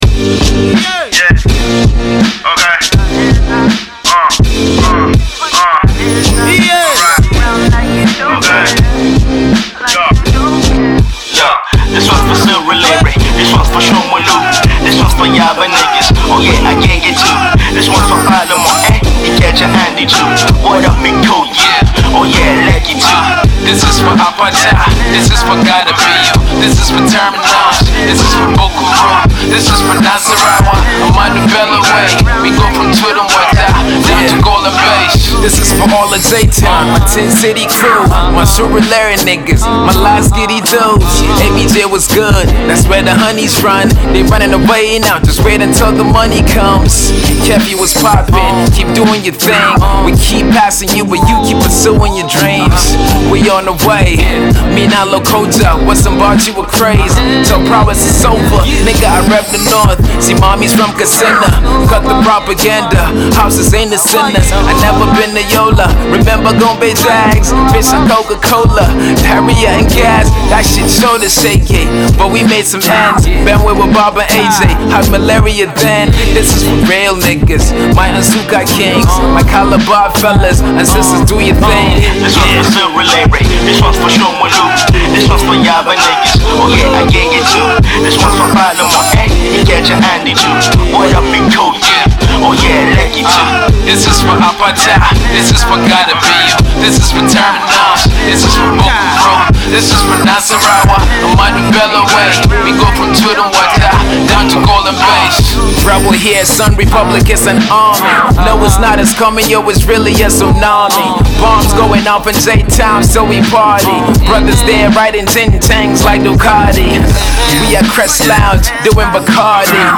all the while staying true to its core hip-hop roots.